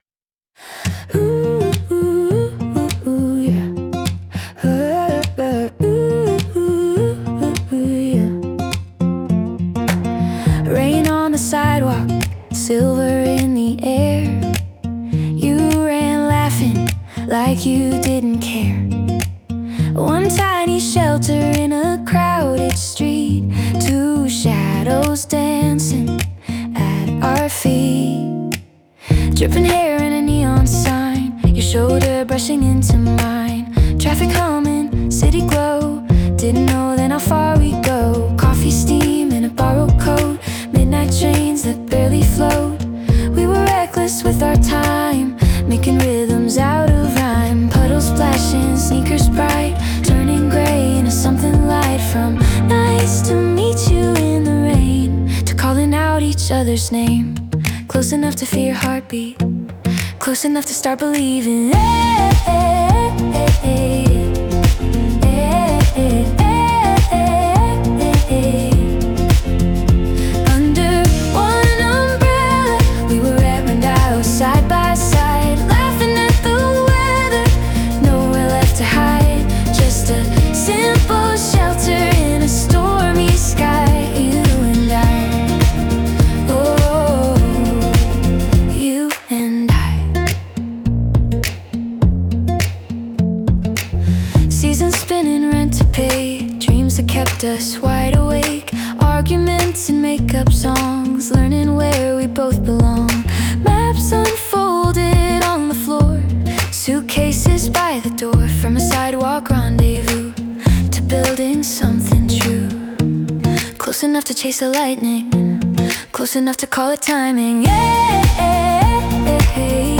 著作権フリーオリジナルBGMです。
女性ボーカル（洋楽・英語）曲です。